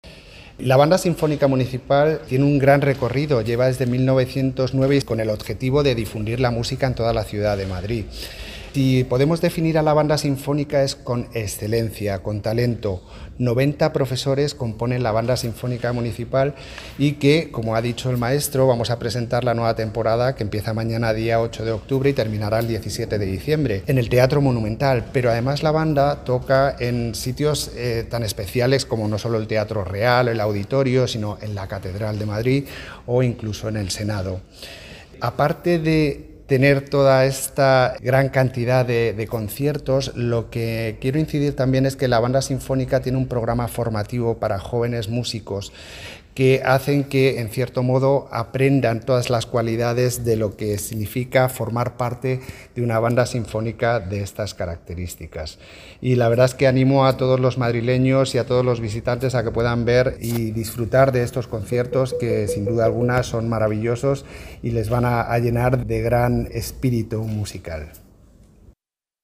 Nueva ventana:Intervención de Rafael Cabrera, Director General de Programas y Actividades Culturales del Ayuntamiento de Madrid